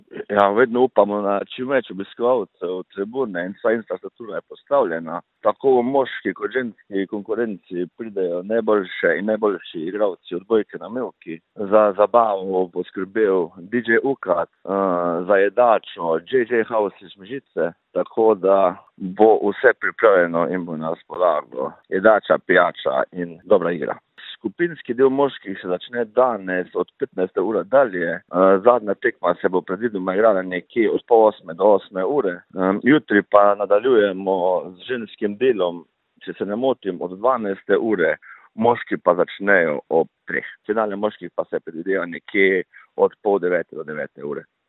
Župan Mežice Mark Maze: